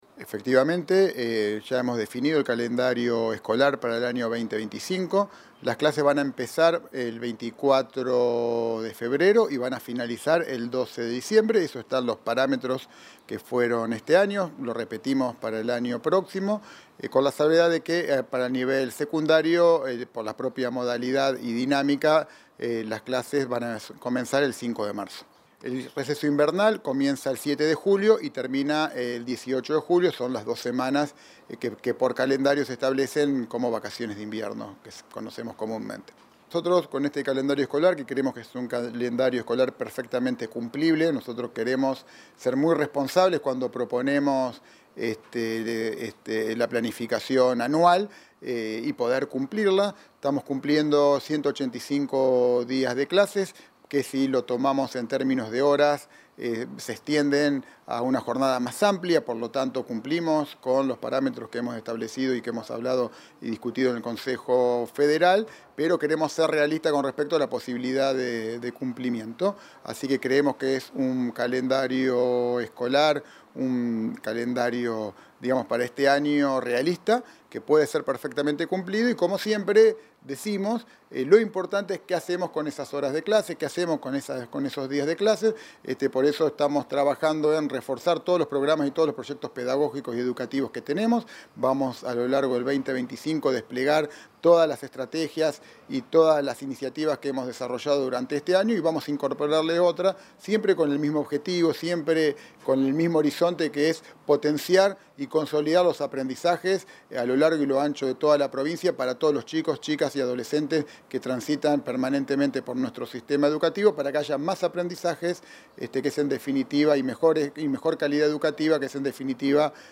Declaraciones del ministro Goity